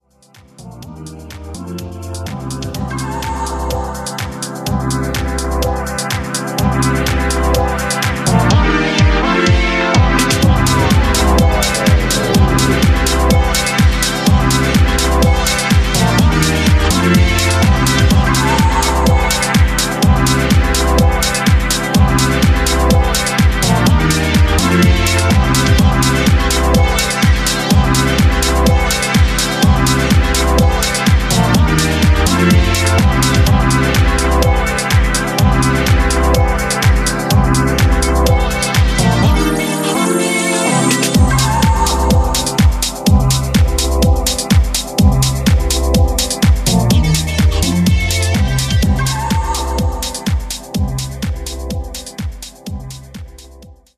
Tracks : 10 House Music Tracks